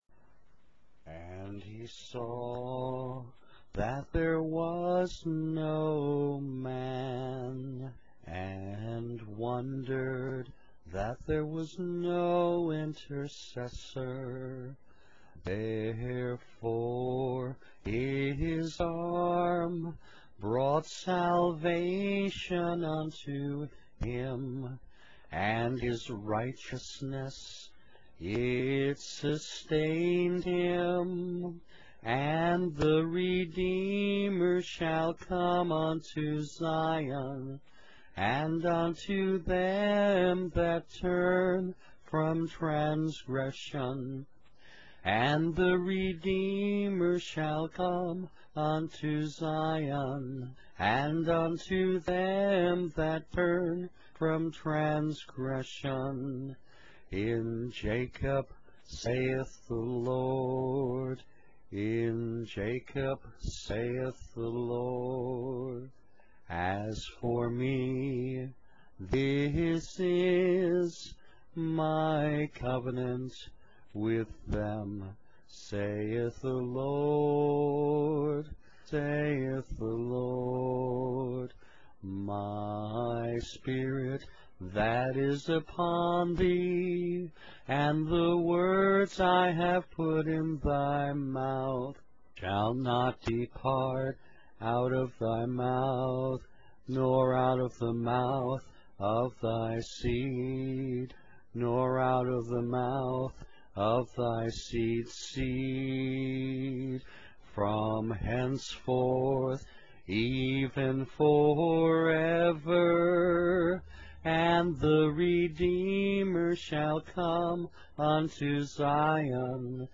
A Cappella Audio Sample (Without instrumental accompaniment)